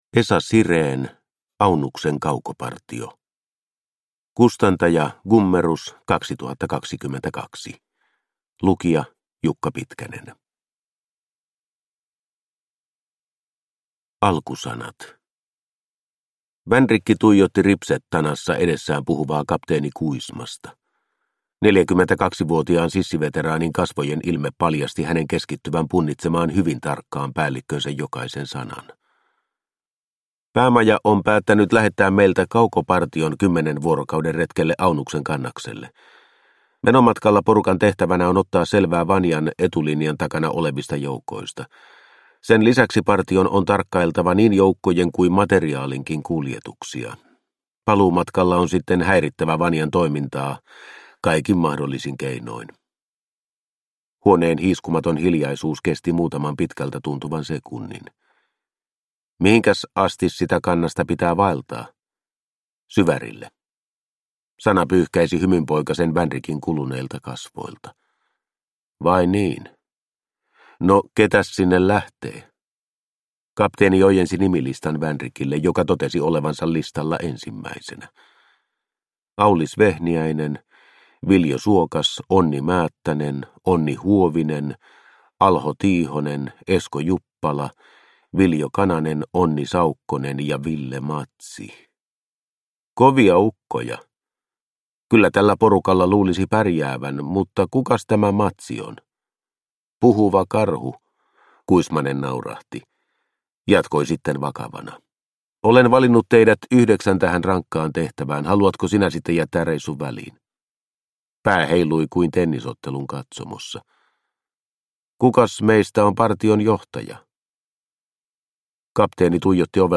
Aunuksen kaukopartio – Ljudbok – Laddas ner